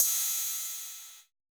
UHH_ElectroHatC_Hit-32.wav